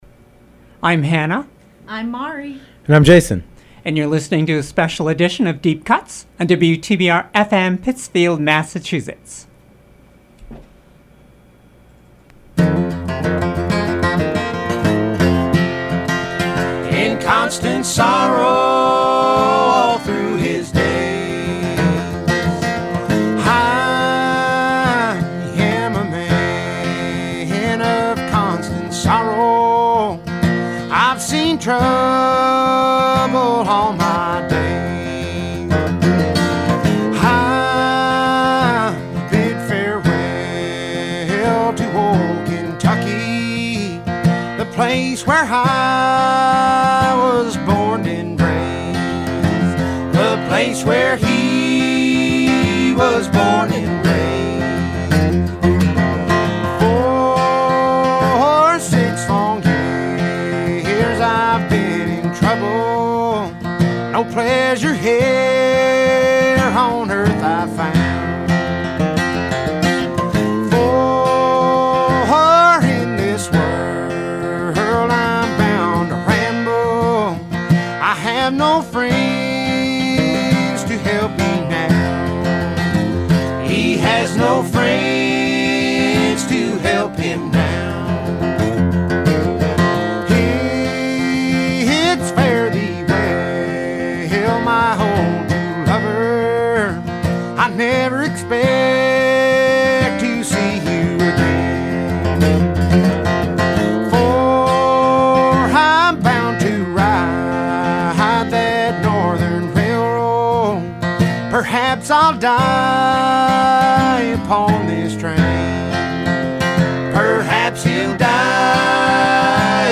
Broadcast live every Thursday afternoon from 4 to 6pm on WTBR.